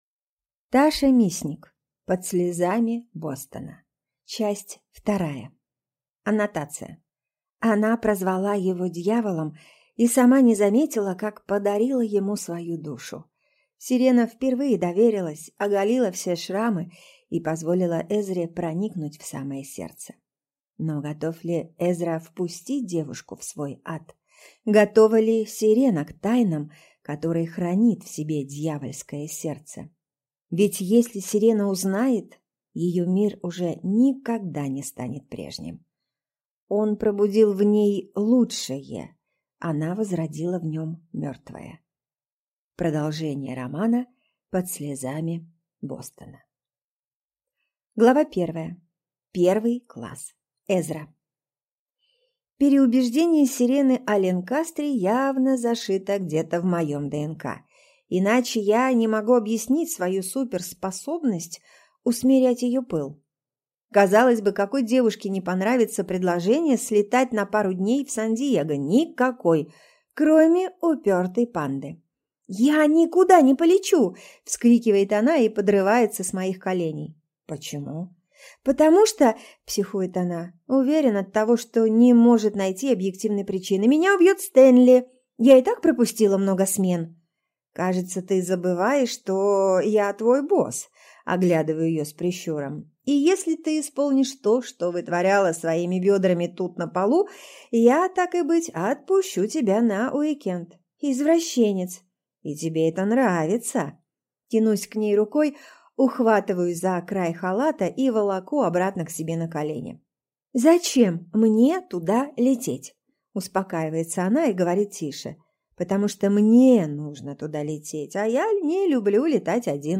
Аудиокнига Под слезами Бостона. Часть 2 | Библиотека аудиокниг